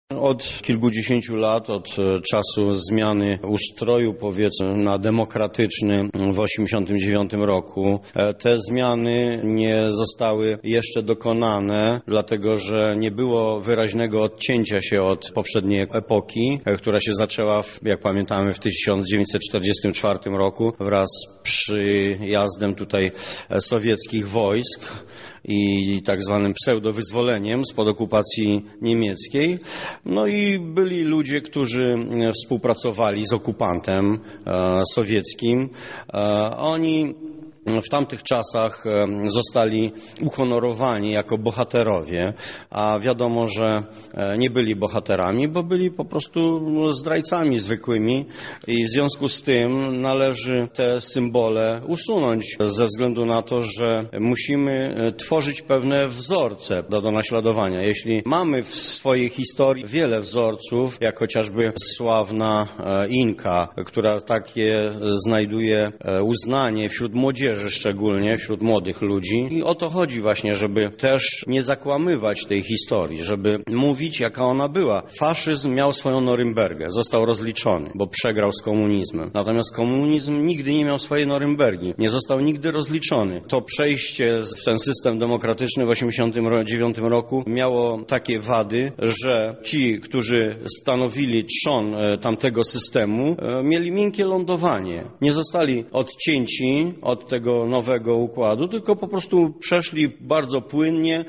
O tym dlaczego należy dekomunizować przestrzeń publiczną mówi sam radny: